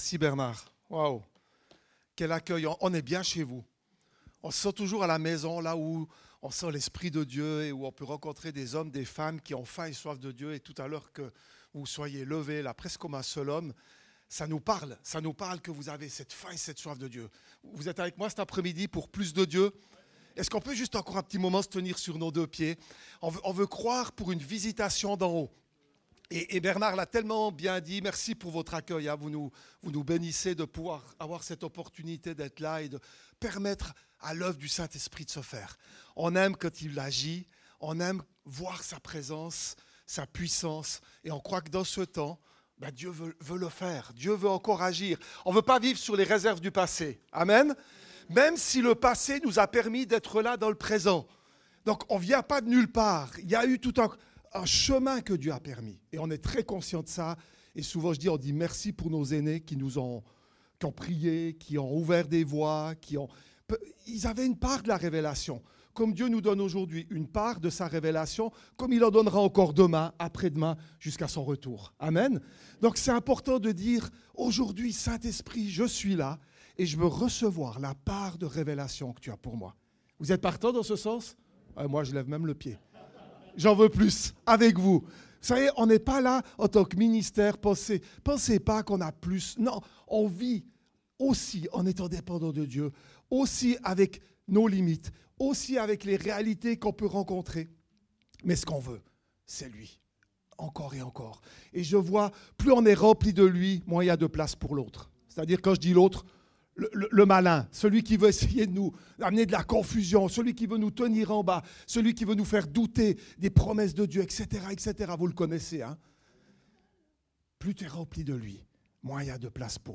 En marche avec le Saint-Esprit Type De Service: Conférence « La prière de louange